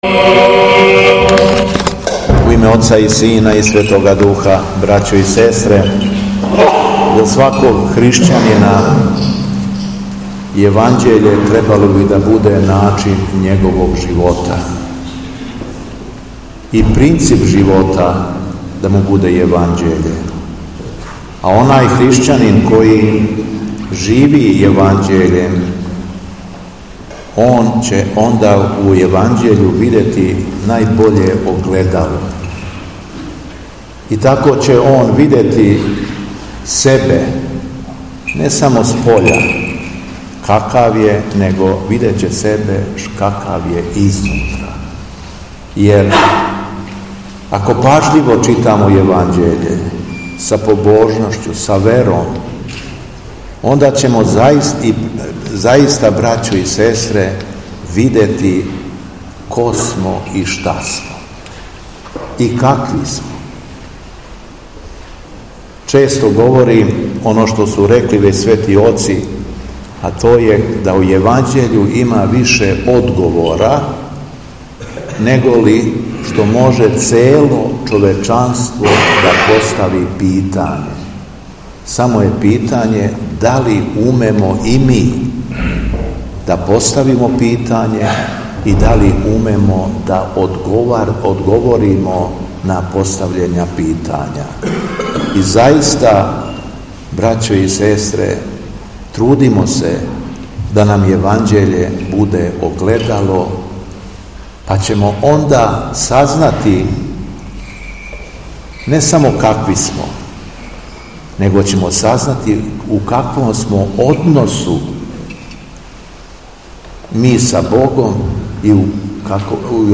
Беседа Његовог Високопреосвештенства Митрополита шумадијског г. Јована
Након прочитаног зачала из Светог Еванђеља Митрополит се обратио верном народу речима: